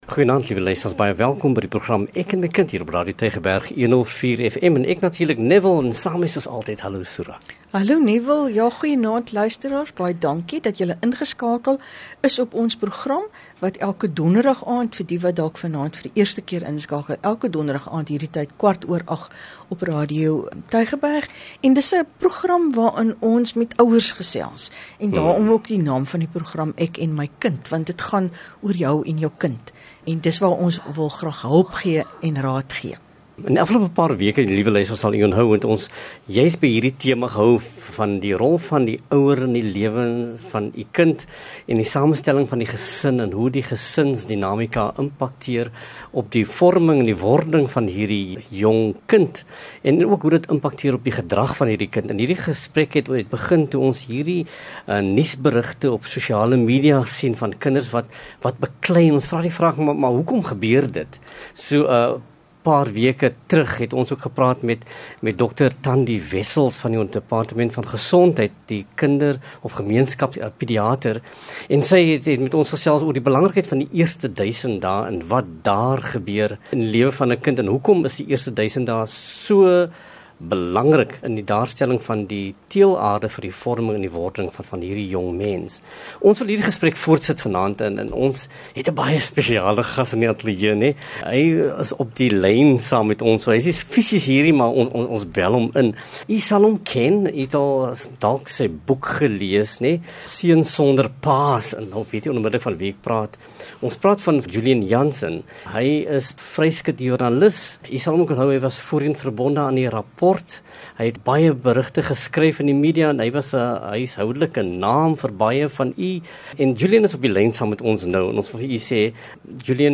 “Ek en my kind” is gespreksprogram wat vanuit ‘n onderwysperspektief ouerleiding bied ten opsigte van kinderontwikkeling en kindersorg. Dit gee ouers perspektief oor Onderwyssake en brandpunte wat impak kan hê op hul rol en verantwoordelikheid ten opsigte van hul kind se welstand in die klaskamer en op die speelgrond.